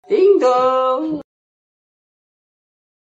jungkooks-ding-dong-notification-ringtone.mp3